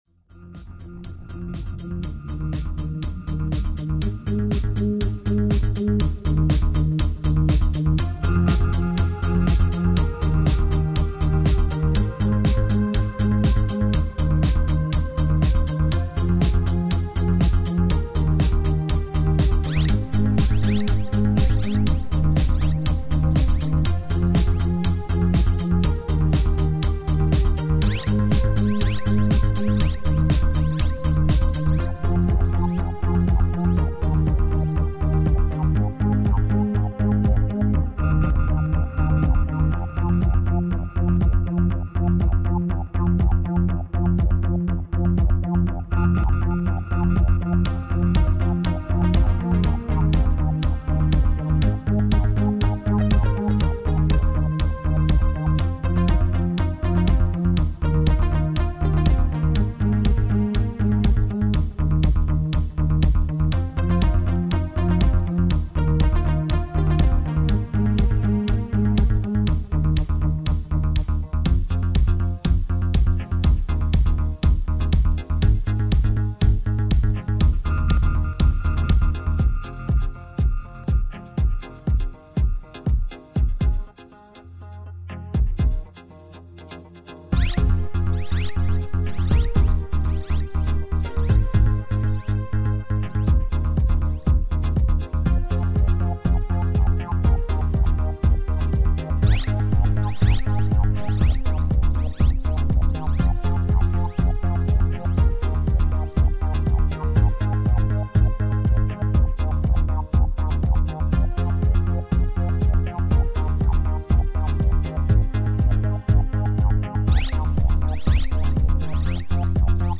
Записал свою первую композицию в стиле 80-х и мне стало очень интересно, что о ней думают окружающие.